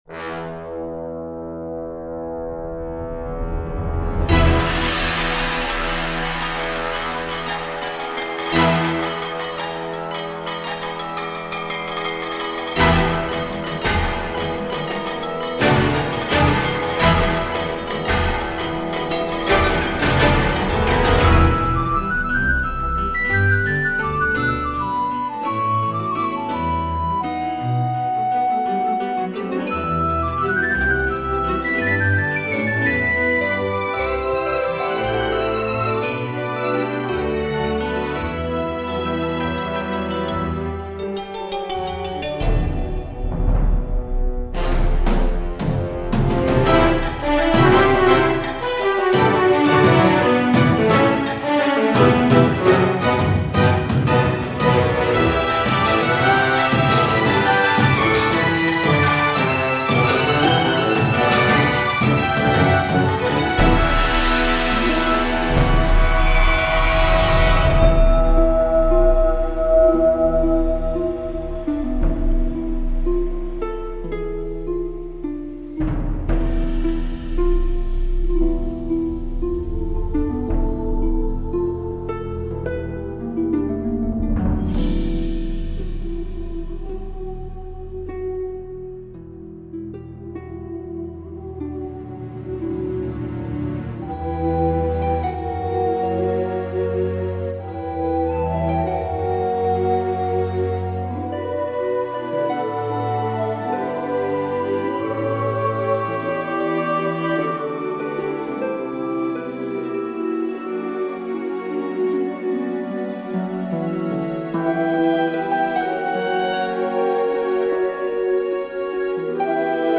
soundtrack/game music